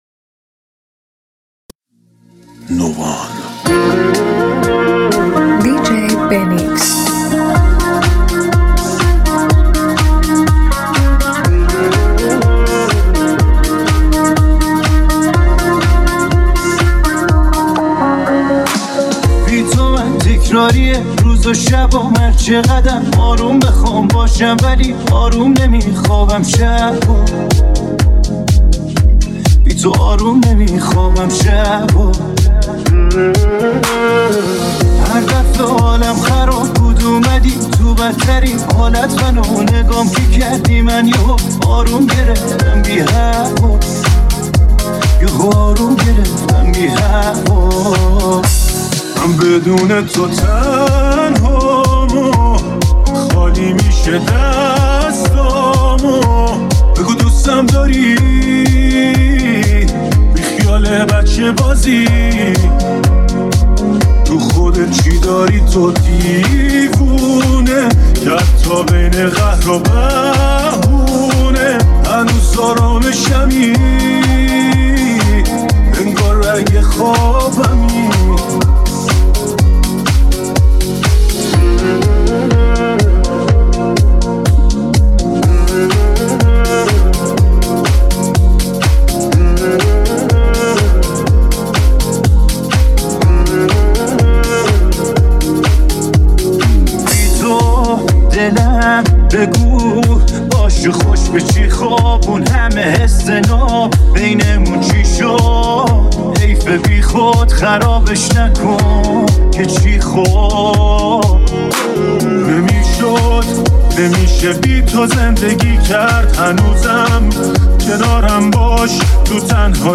یه فضای متفاوت و پر از انرژی که از لحظه اول به دامش می‌افتی.